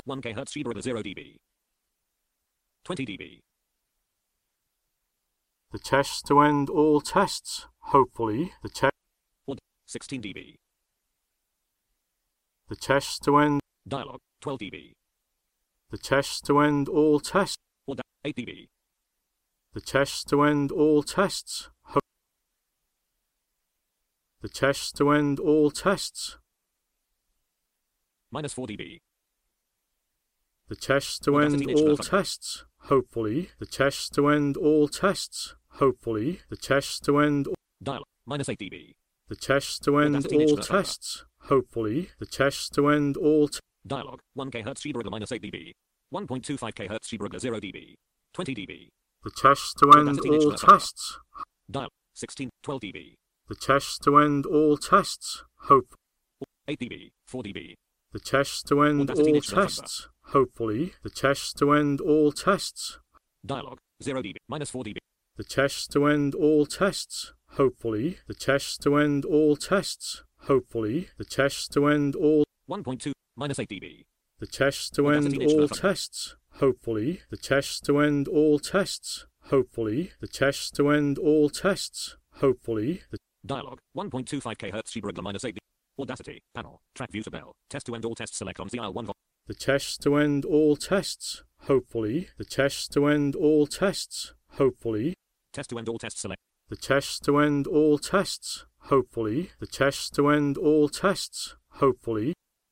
You’ll immediately hear the resonance in the preview.
This real time recording shows how I do it (in the equalization dialog)
The A/B comparison is at the very end.